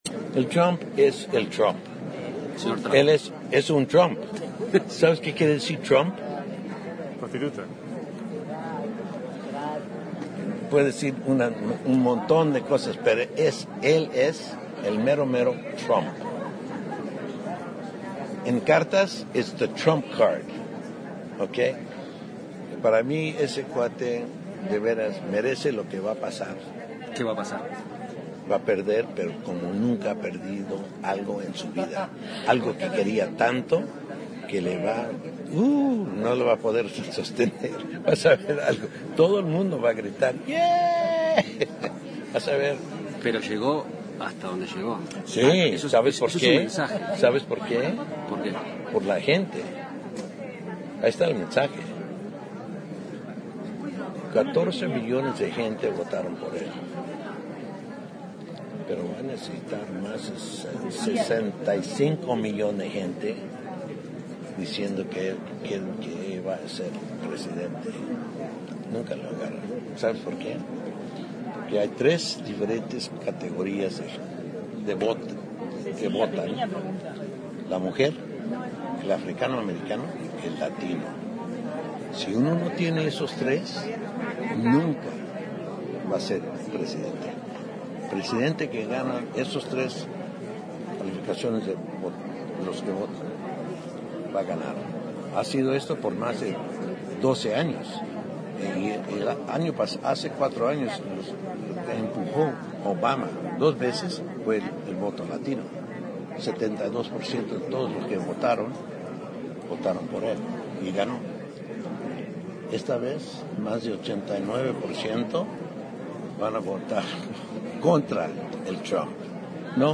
Entrevista
Entrevistado por El Espectador en los Premios Platino, la cara de alegría de estar en la alfombra roja se le transformó al escuchar su nombre.